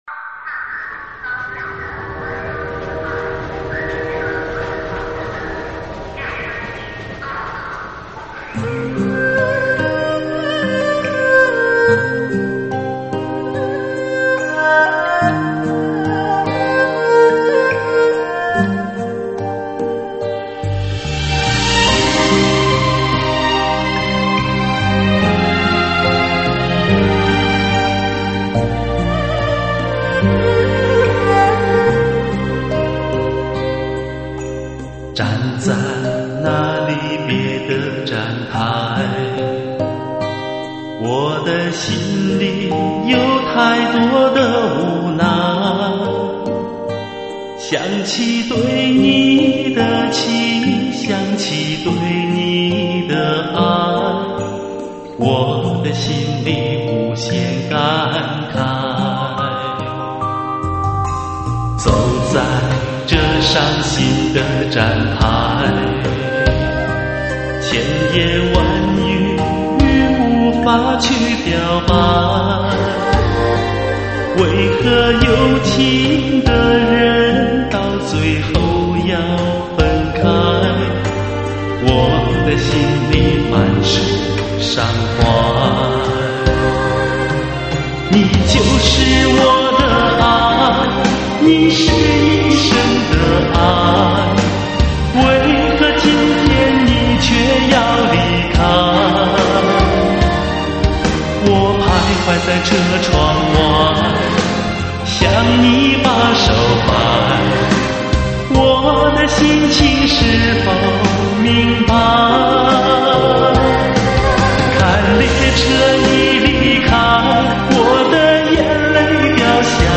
中文舞曲
舞曲类别：中文舞曲